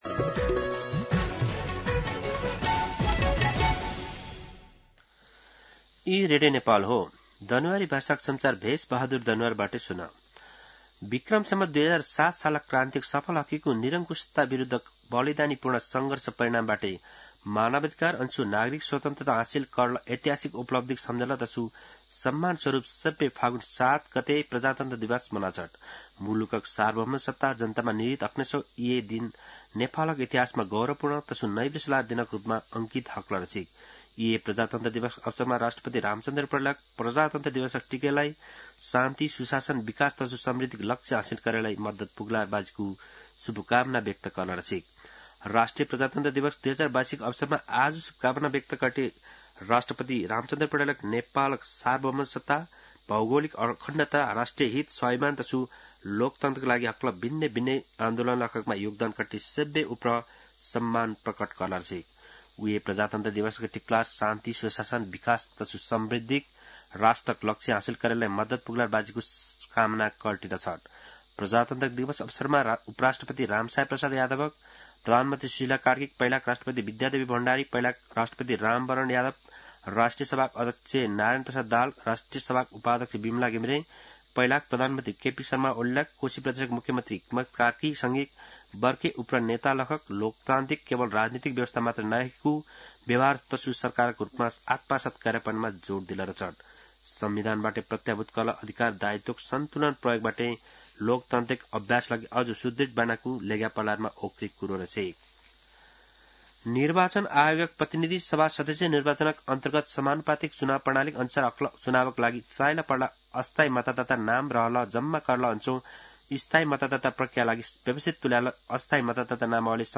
दनुवार भाषामा समाचार : ७ फागुन , २०८२
danuwar-news.mp3